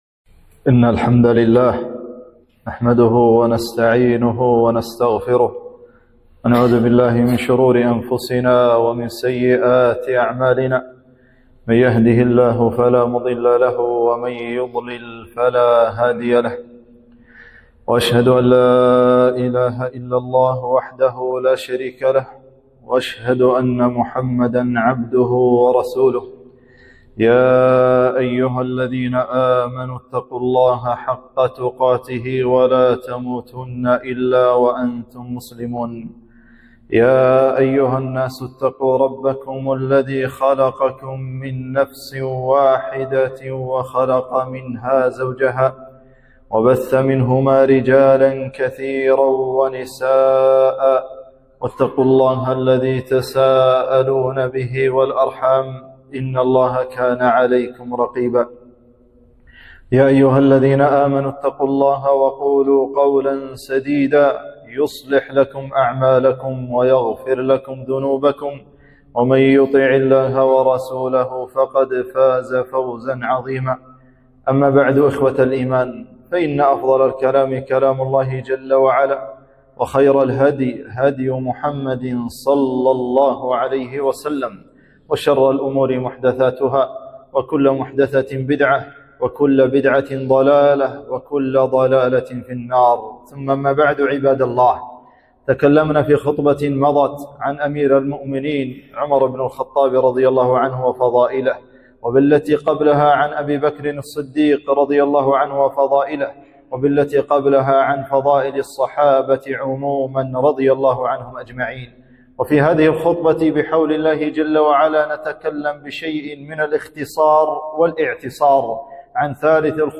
خطبة - فضائل عثمان بن عفان رضي الله عنه